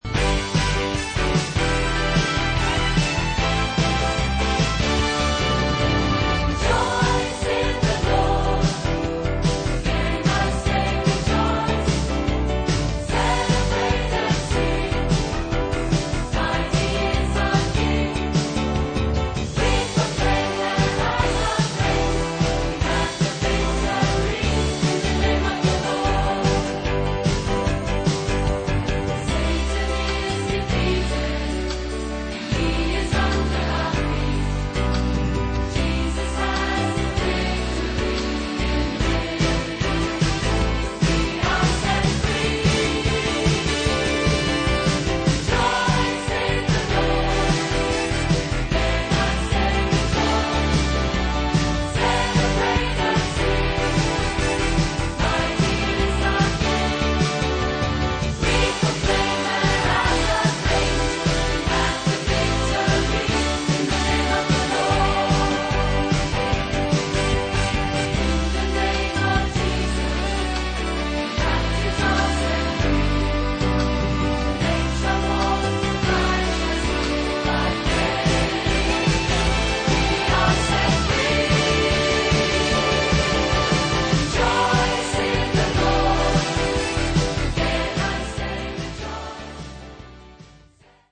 Another great rejoicing song